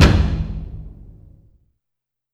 Shumei Taiko (Coldest Winter).wav